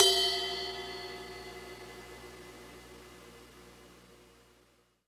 Ride_7.wav